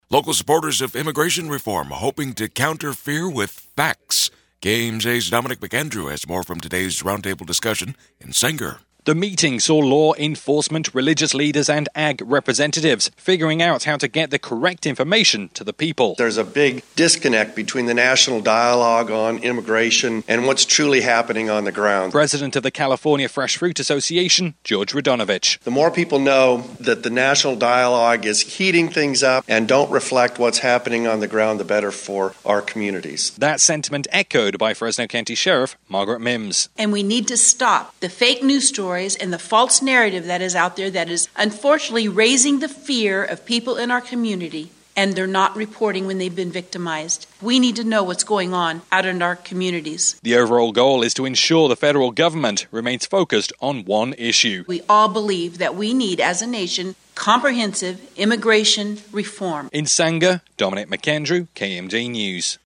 Religious leaders, representatives from ag groups, and law enforcement, came together Thursday morning for a round-table discussion inside Sanger’s Tabernacle of Praise Christian Center to examine the situation for those in the country illegally.